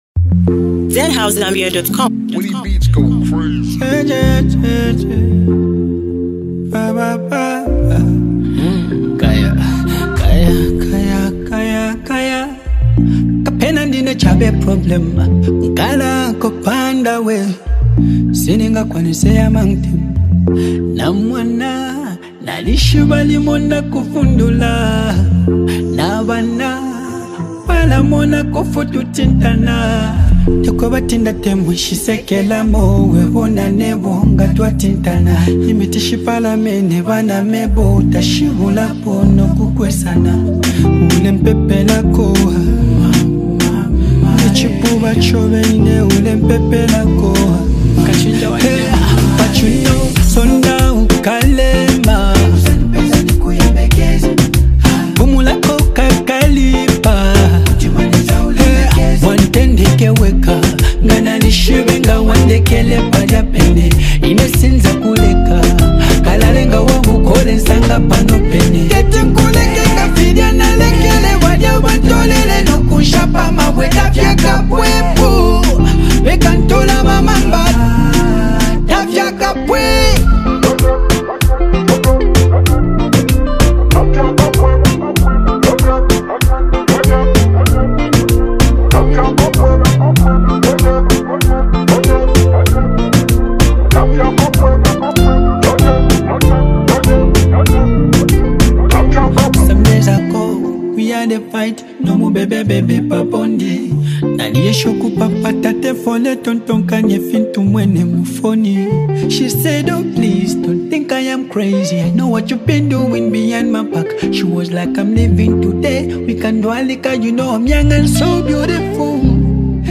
vibrant anthem